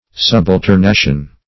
Meaning of subalternation. subalternation synonyms, pronunciation, spelling and more from Free Dictionary.